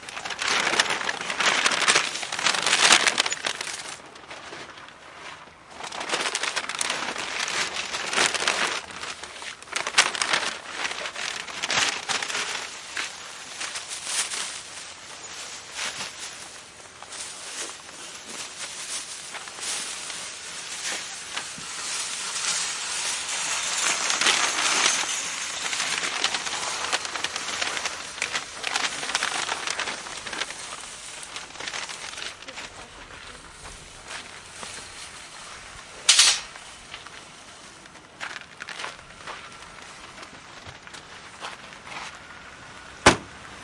棕色纸袋
描述：棕色纸袋压皱，饺子，包装。在Zoom H4N上使用Rode麦克风录制。
标签： 揉碎 包装 手提袋 包装 纸袋
声道立体声